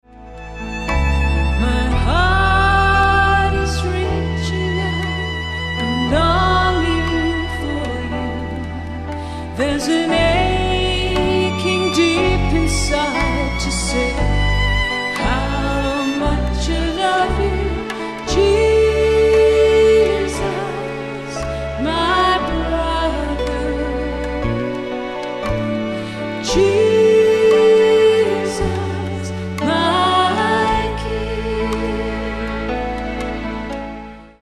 worshipful music